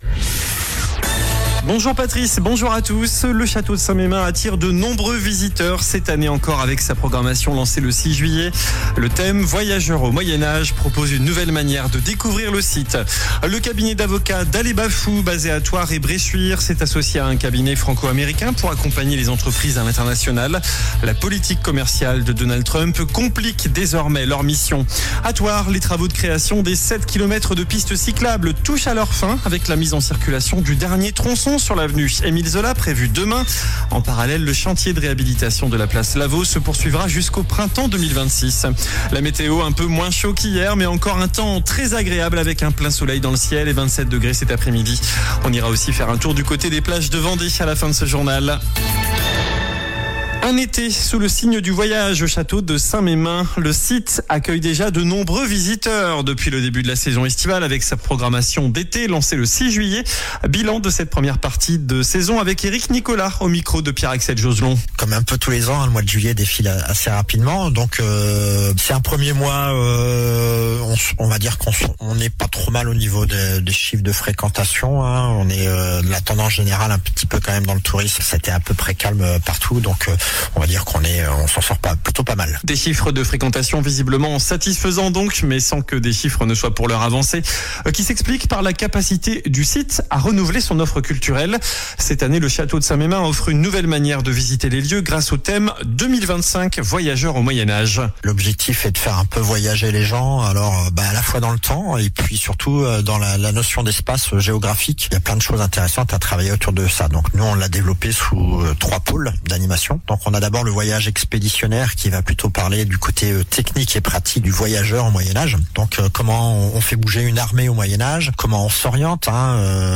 JOURNAL DU MARDI 05 AOÛT ( MIDI )